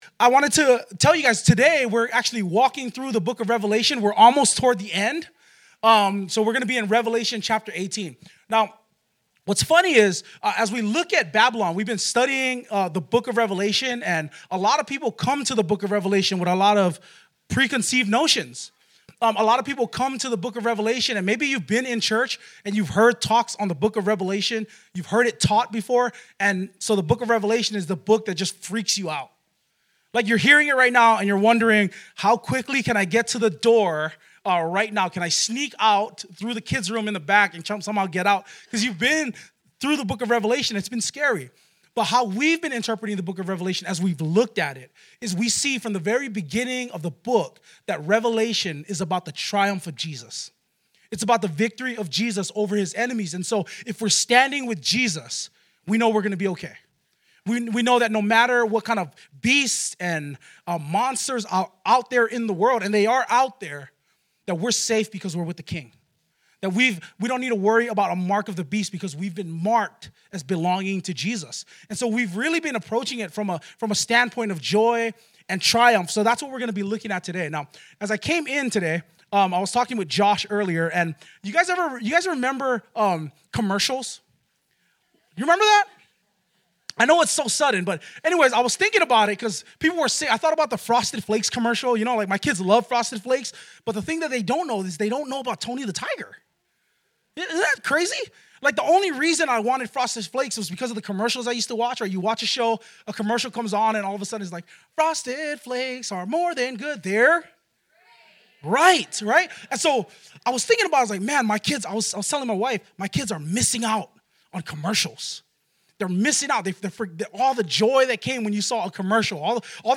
2023 The Triumph Brings Freedom Preacher